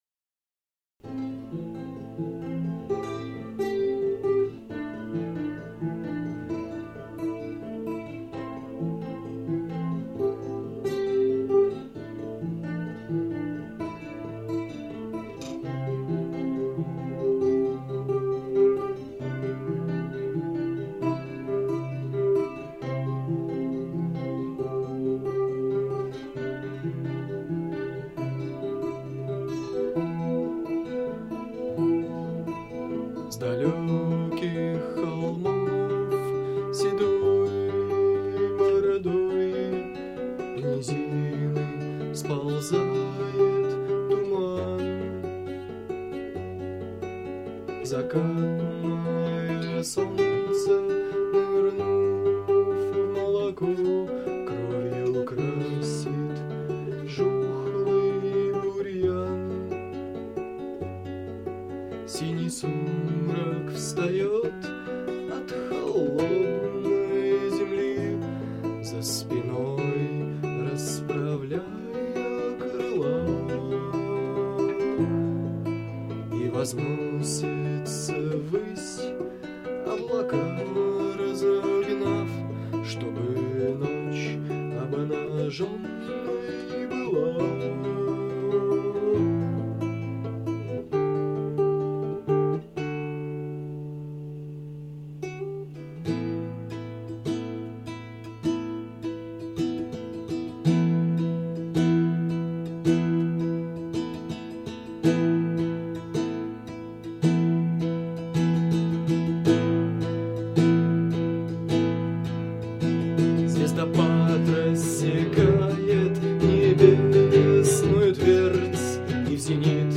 [128 / Stereo]